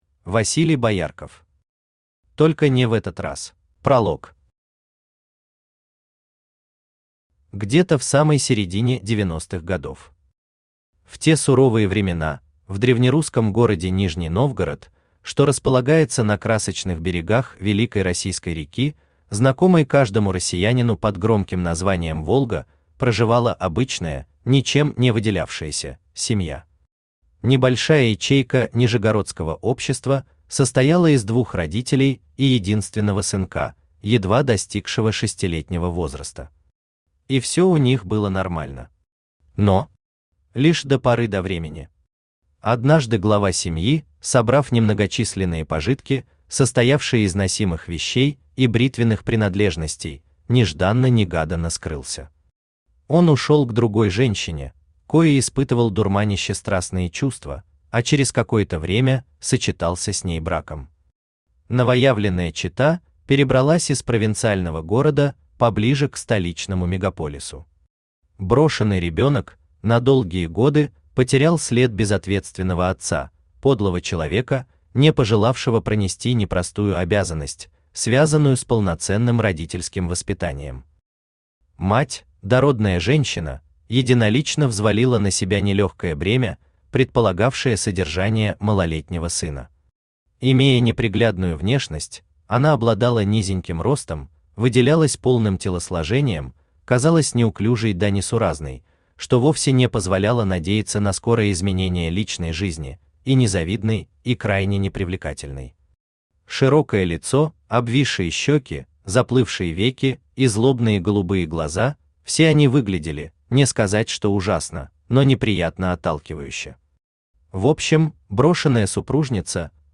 Aудиокнига Только не в этот раз Автор Василий Боярков Читает аудиокнигу Авточтец ЛитРес.